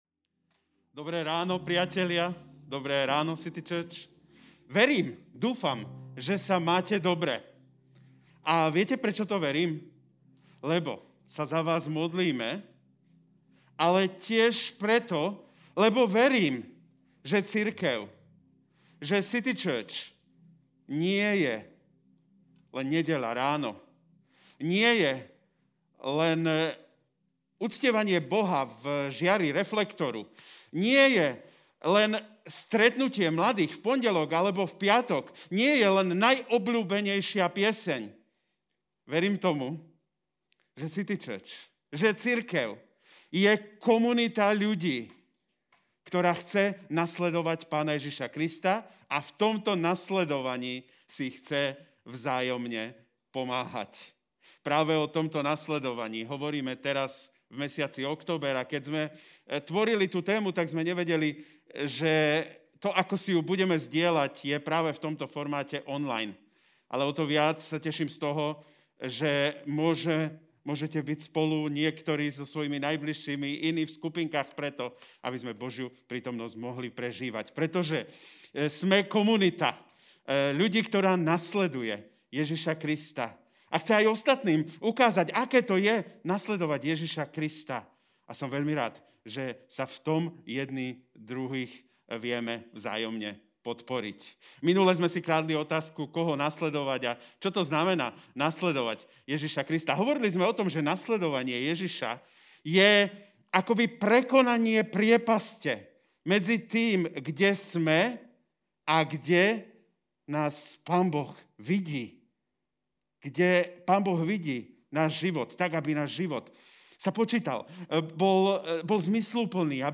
Keď Ježiš spí Kázeň týždňa Zo série kázní